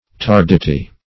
tardity - definition of tardity - synonyms, pronunciation, spelling from Free Dictionary Search Result for " tardity" : The Collaborative International Dictionary of English v.0.48: Tardity \Tar"di*ty\, n. [L. tarditas.]